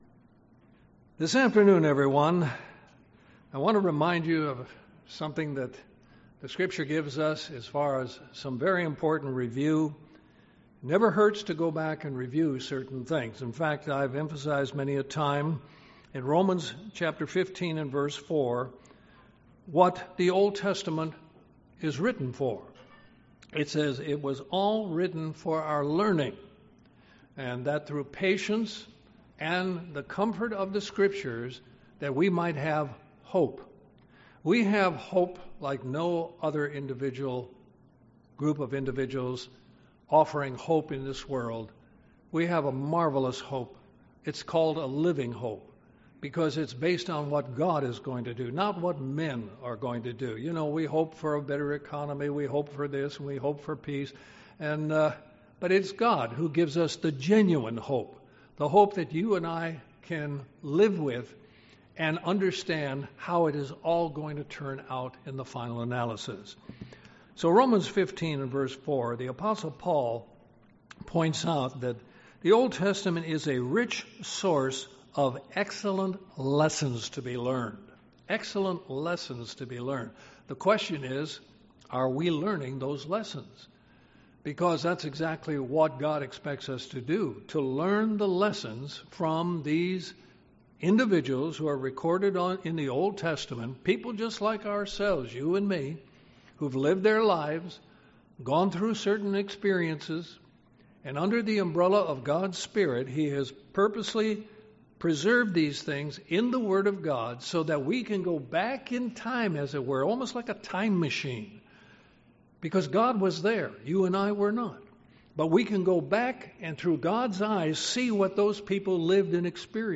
Sermons
Given in Columbus, GA Central Georgia